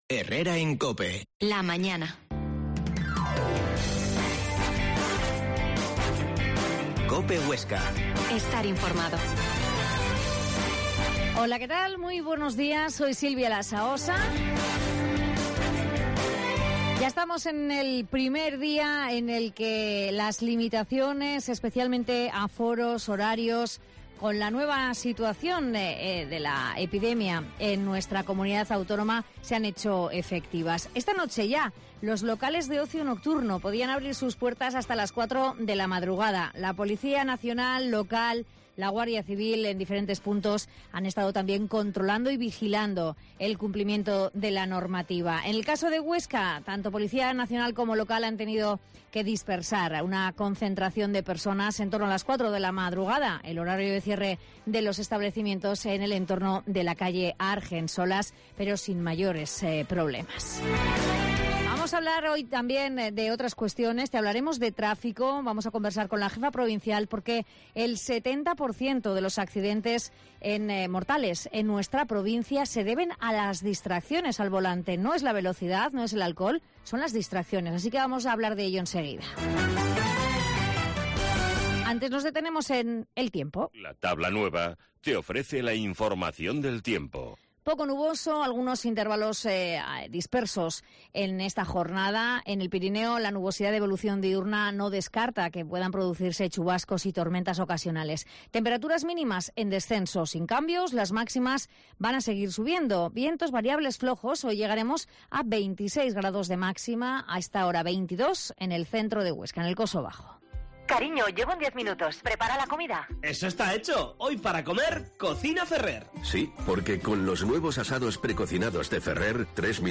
Herrera en COPE Huesca 12.50h Entrevista a la jefa provincia de tráfico, Margarita Padial